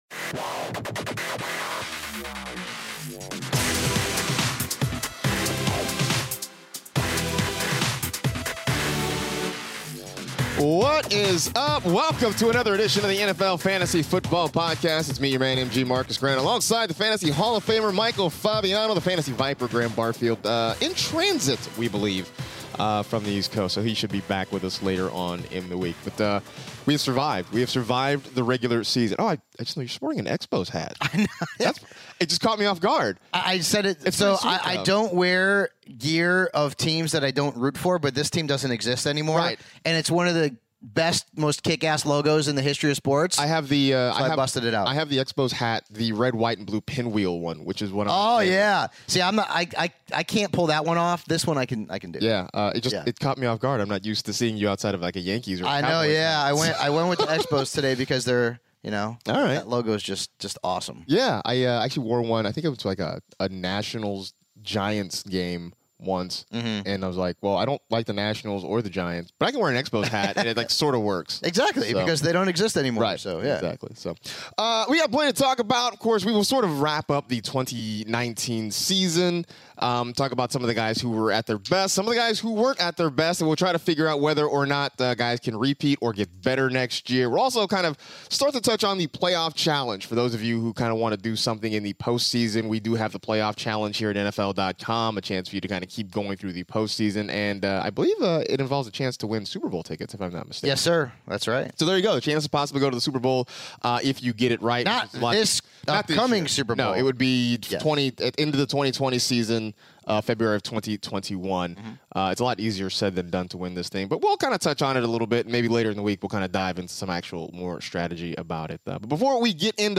are back in the studio to wrap up the 2019 NFL regular season!